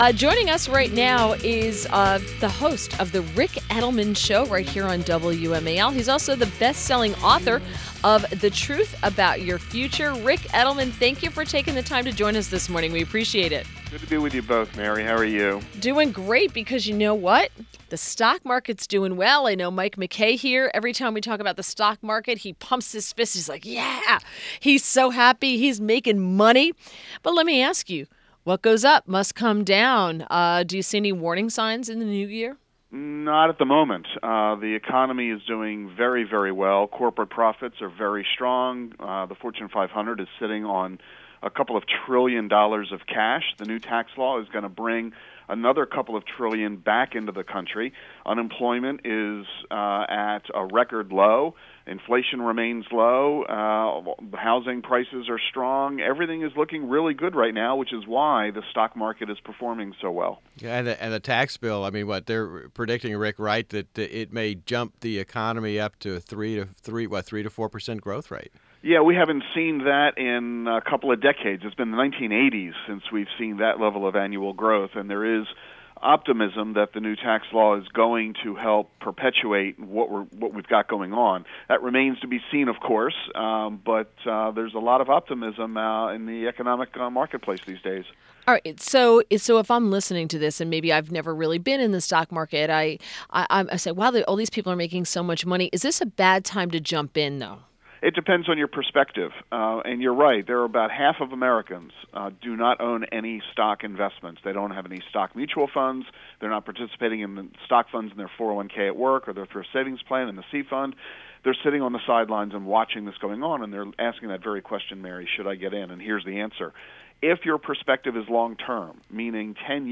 WMAL Interview - RIC EDELMAN - 12.26.17